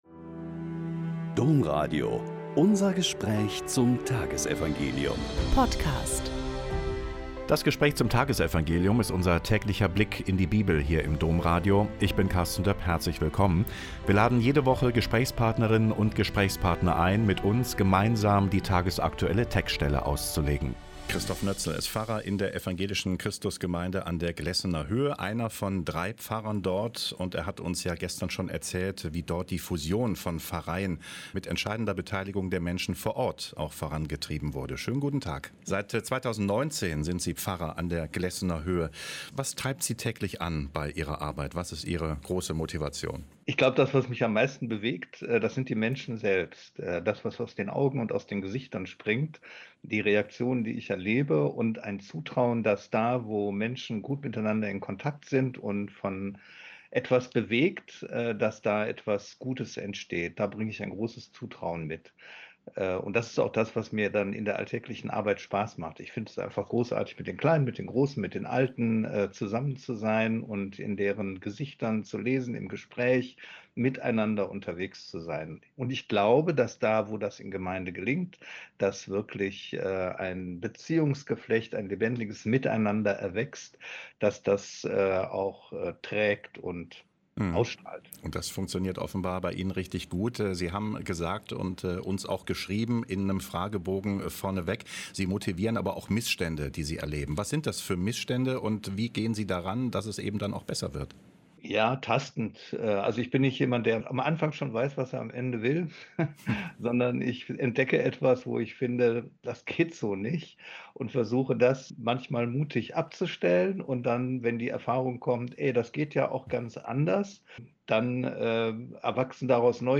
Mt 8, 23-27 - Gespräch